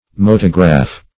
motograph - definition of motograph - synonyms, pronunciation, spelling from Free Dictionary
Motograph \Mo"to*graph\, n. [L. movere, motum, to move +